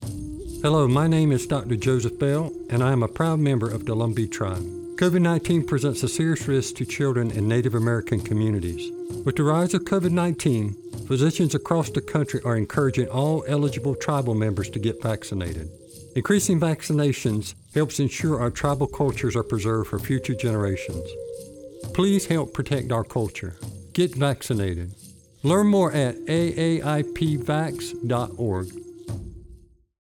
Radio / Televsion PSAs